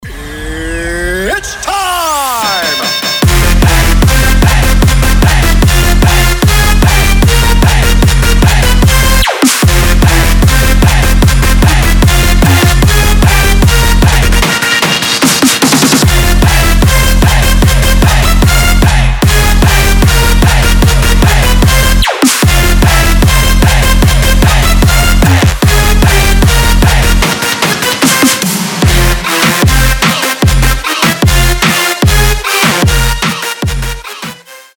• Качество: 320, Stereo
громкие
мощные
EDM
энергичные
динамичные
Стиль: big room, electro house